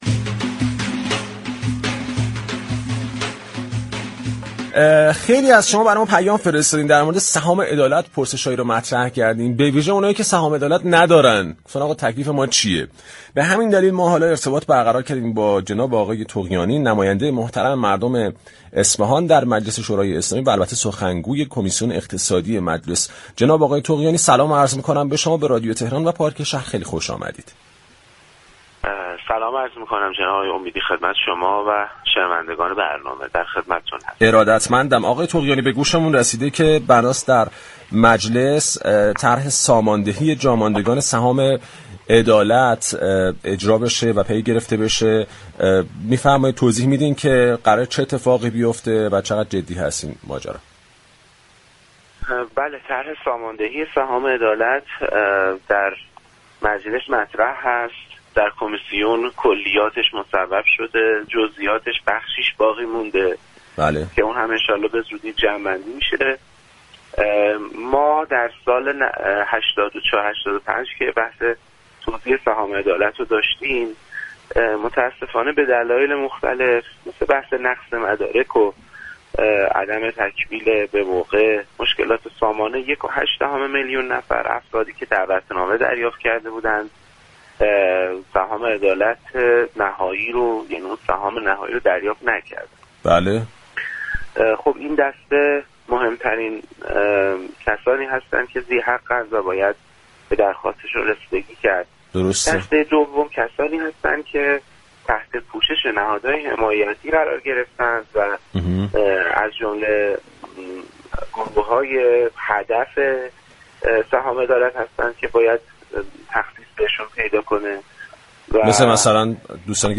مهدی طغیانی، نماینده اصفهان در مجلس شورای اسلامی و سخنگوی كمیسیون اقتصادی مجلس، در رابطه با توزیع سهام عدالت با پارك شهر رادیو تهران گفتگو كرد.